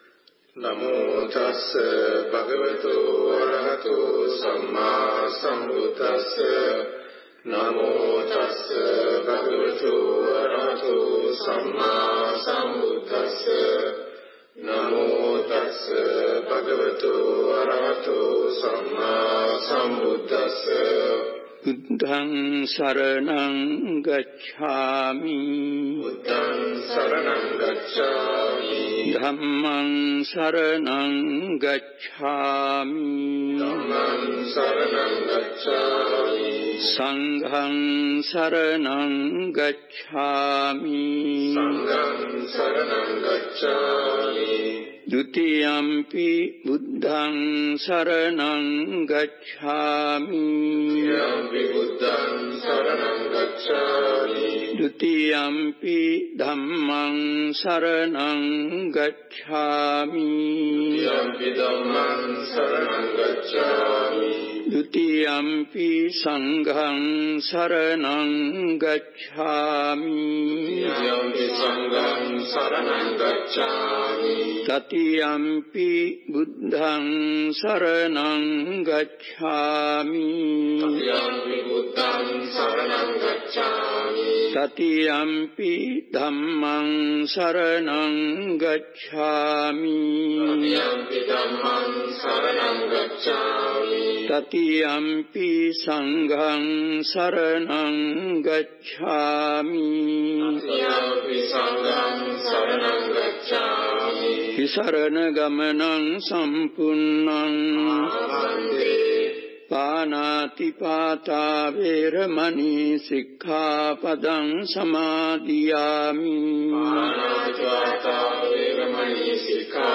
The five precepts (panca sila; pronounced "pancha seela") are normally recited after paying homage to the Buddha (Namaskaraya).